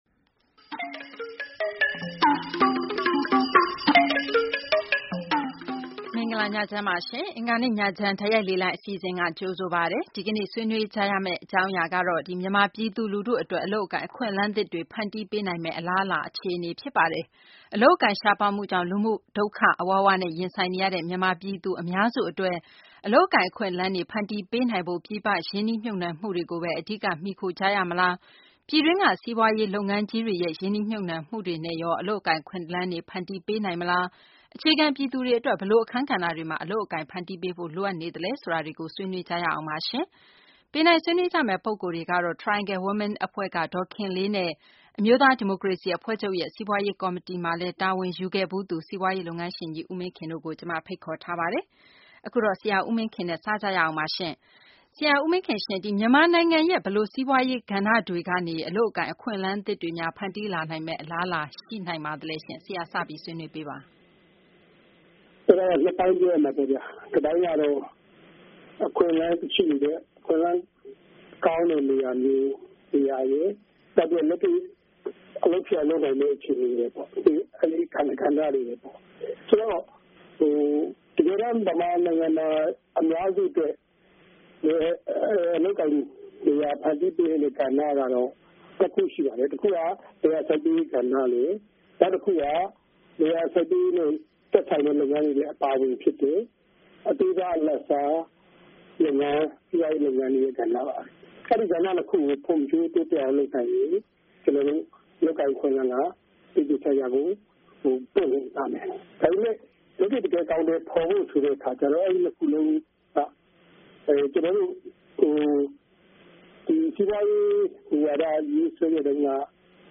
အလုပ်အကိုင် အခွင့်အလမ်းသစ်တွေ ဖန်တီးပေးဖို့ အတွက် မူဝါဒတွေ ရှိပေမယ့် လက်တွေ့ အကောင်အထည် ဖော်နိုင်မယ့် နည်းလမ်းတွေ လိုအပ်နေသေးတဲ့ အခြေအနေတွေကို ဆွေးနွေးထားတဲ့ အင်္ဂါနေ့ညချမ်း တိုက်ရိုက် လေလှိုင်း အစီအစဉ်ကို နားဆင်နိုင်ပါတယ်။